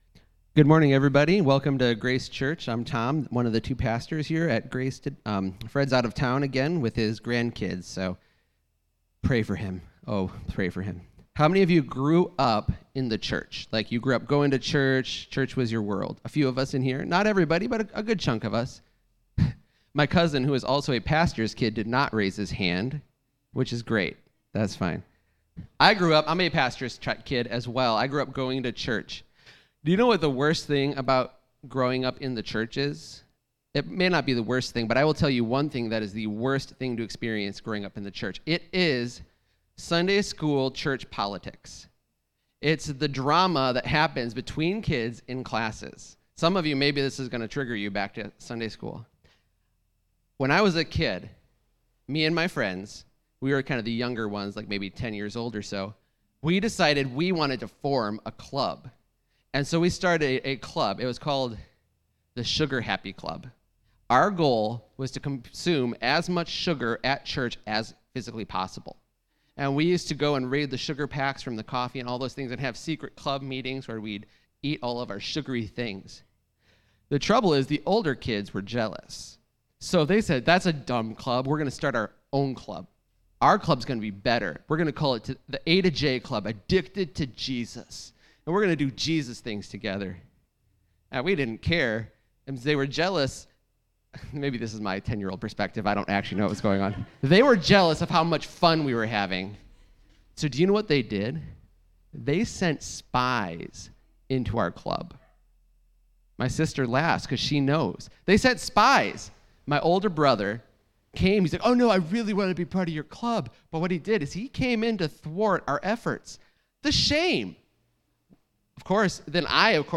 Jul 26, 2020 The Cross (07.26.2020) MP3 PDF SUBSCRIBE on iTunes(Podcast) Notes Discussion Sermons in this Series The ultimate joy will be when we are with Christ in heaven. If we remain faithful to the cross of Jesus Christ, we will experience the power of God to bring us into His eternal kingdom.